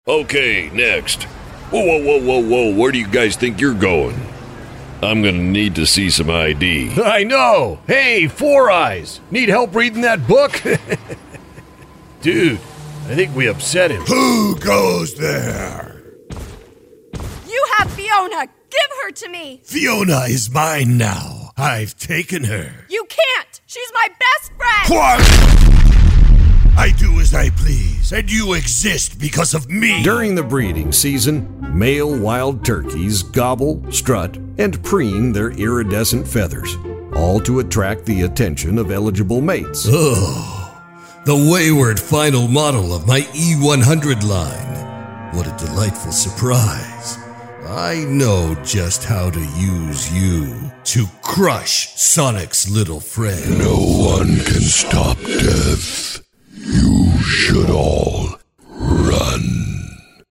Character, Cartoon and Animation Voice Overs
Adult (30-50) | Older Sound (50+)
0110Character_Demo.mp3